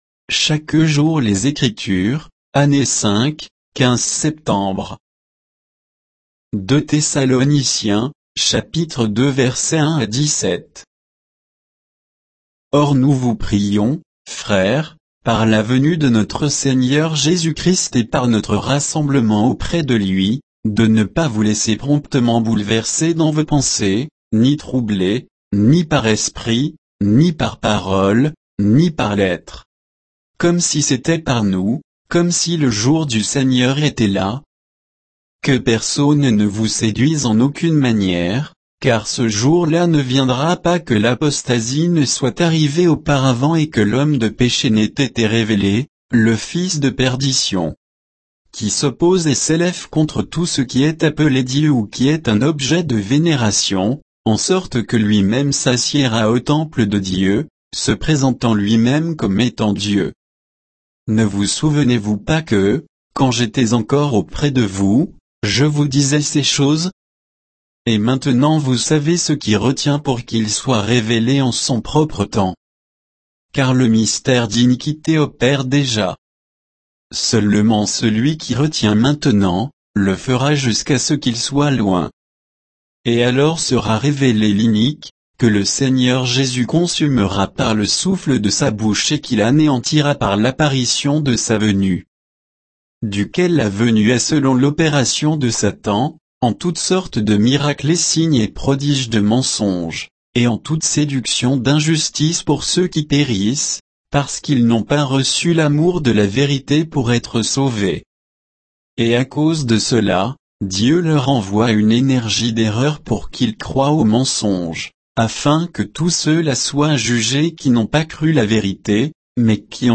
Méditation quoditienne de Chaque jour les Écritures sur 2 Thessaloniciens 2, 1 à 17